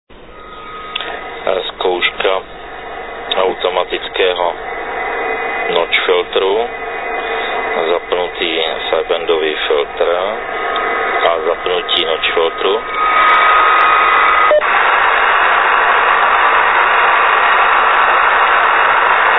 Automatický notch filtr FT1000